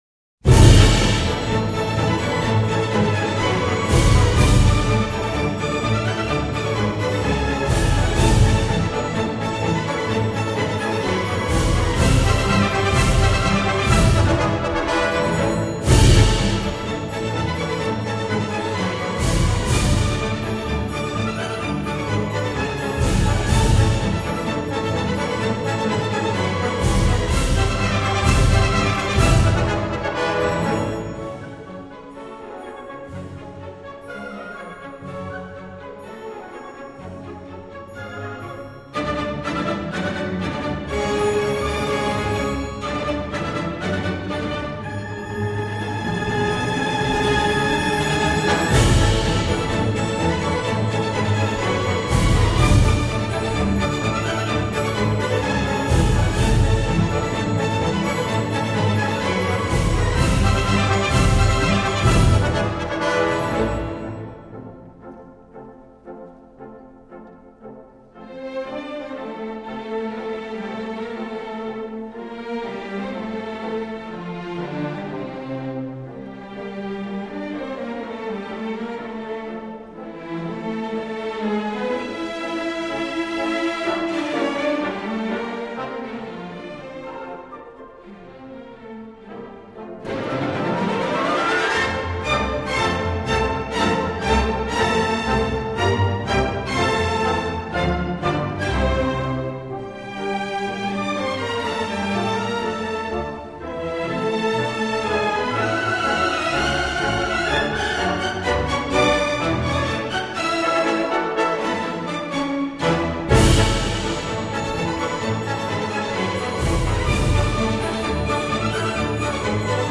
难关：一开始的强烈总奏是否就让您的喇叭破功了？
三角铁也要随时展现清亮香甜的音质。